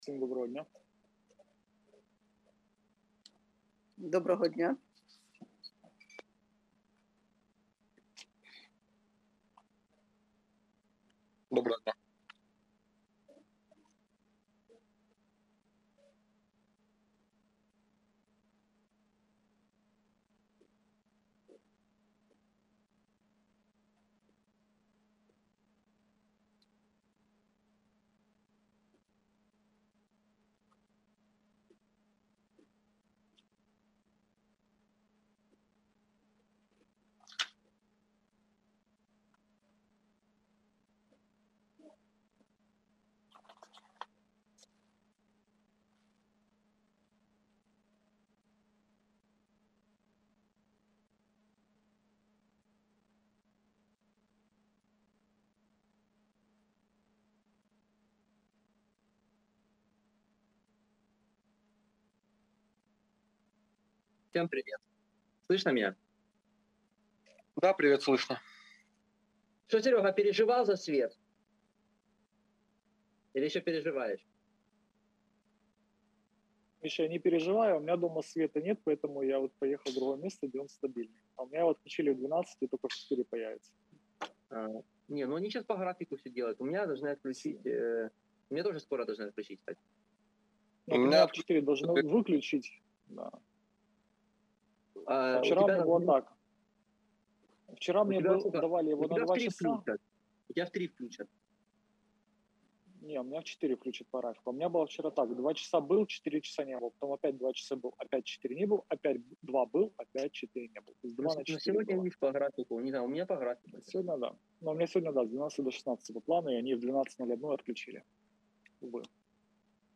Аудіозапис засідання Комітету від 18.11.2022